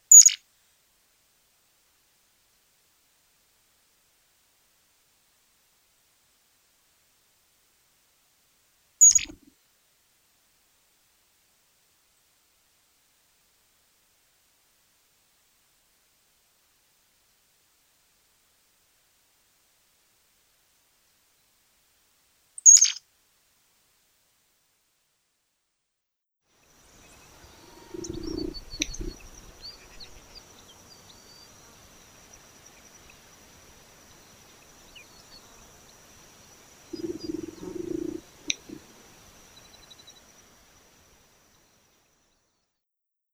Hymenops perspicillata perspicillata - Pico de plata
Picodeplata.wav